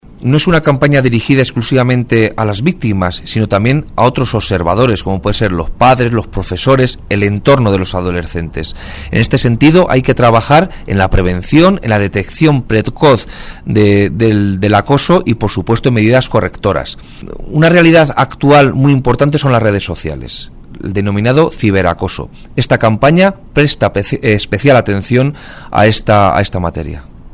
El delegado de la Junta en Guadalajara habla de la campaña contra el acoso escolar puesta en marcha por el Gobierno regional